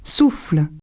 souffle0.mp3